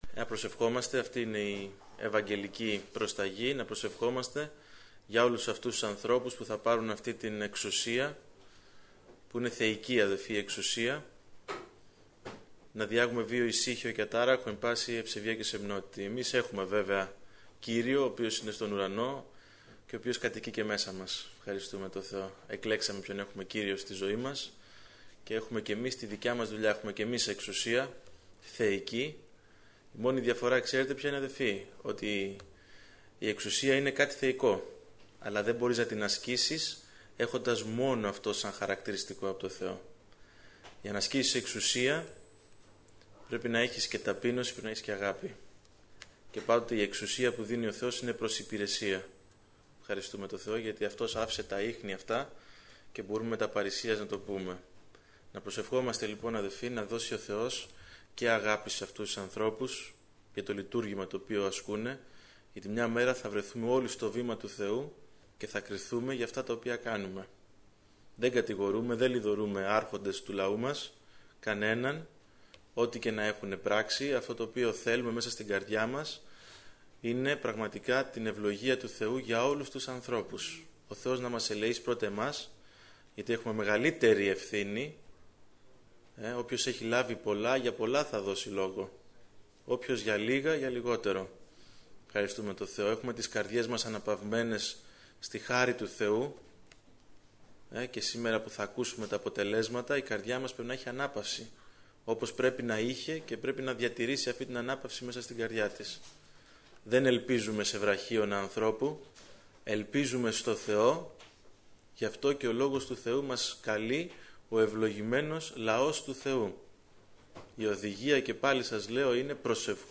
06/05/2012 Ομιλητής: Νεολαία Αγίας Παρασκευής Λεπτομέρειες Σειρά: Κηρύγματα Ημερομηνία: Δευτέρα, 07 Μαΐου 2012 Εμφανίσεις: 711 Γραφή: Ματθαίος 24:3; Ματθαίος 25:14 Λήψη ήχου Λήψη βίντεο